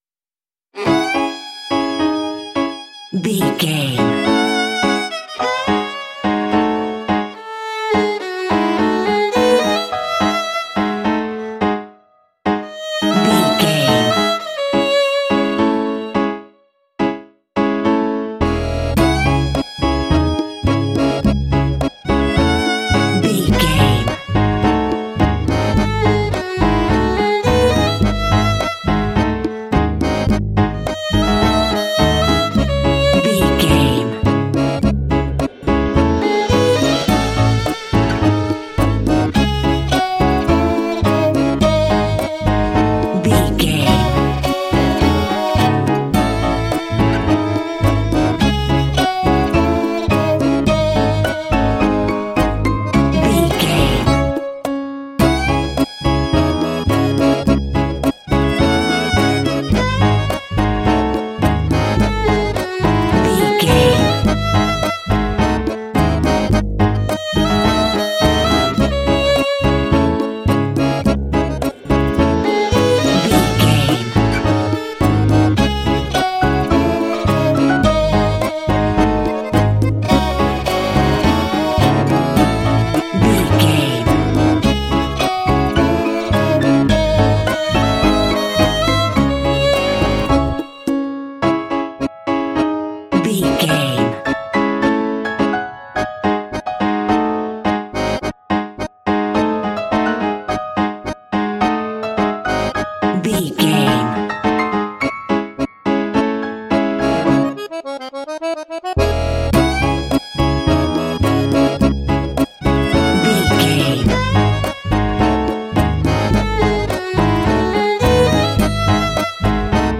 Aeolian/Minor
D♭
Slow
sultry
haunting
melancholic
dramatic